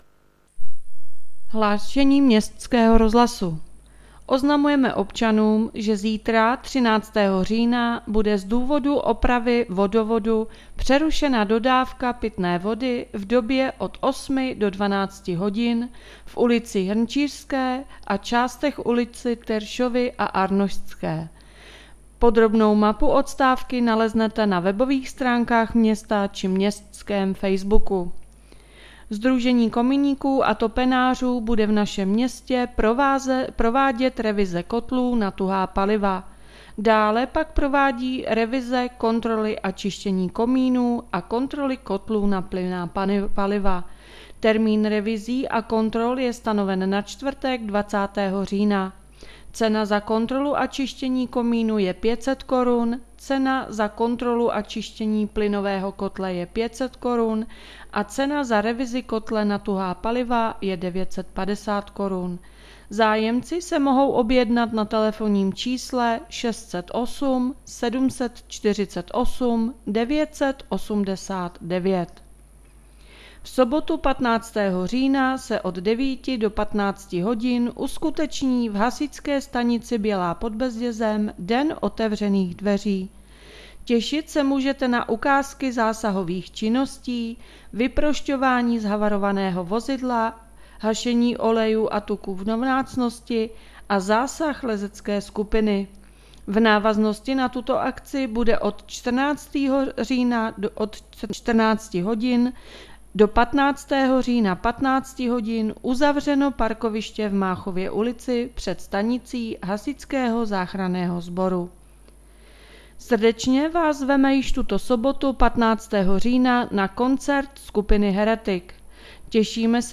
Hlášení městského rozhlasu 12.10.2022